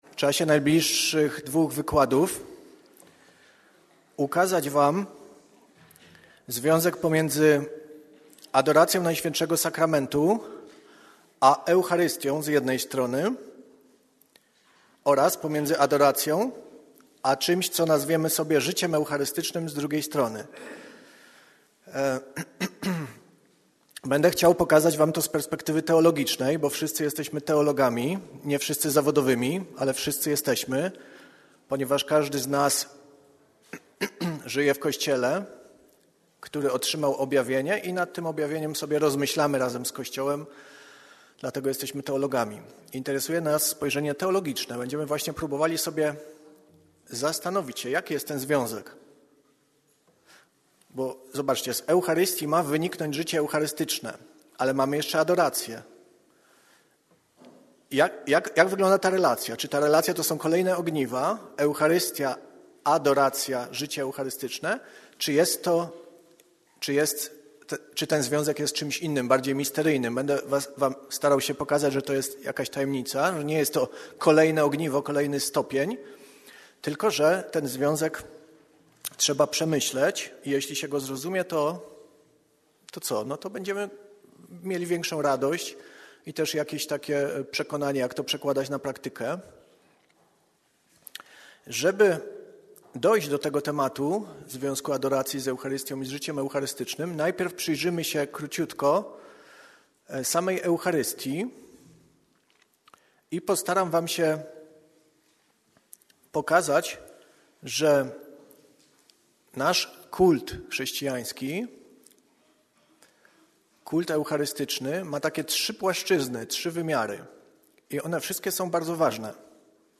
Zjazd UTW 25 marca 2023 r. - wykład i zdjęcia